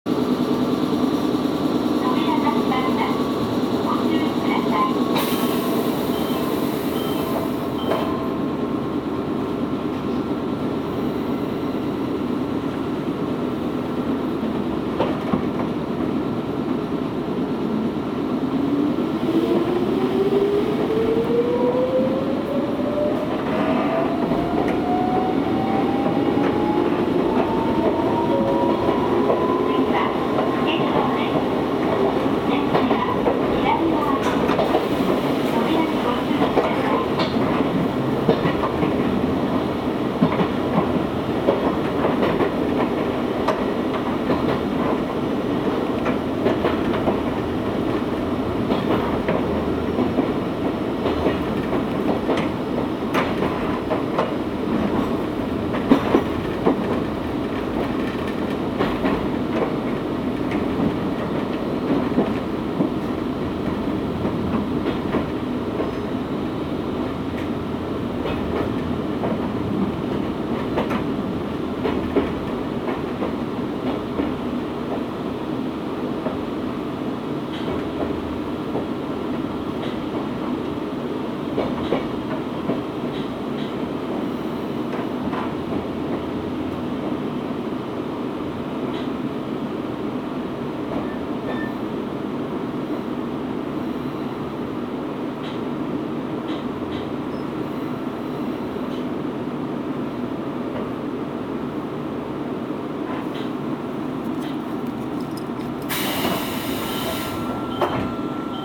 走行機器は抵抗制御で、架線電圧変更の過渡期に導入されたため、複電圧車両となっています。
走行音
録音区間：深日港～深日町(お持ち帰り)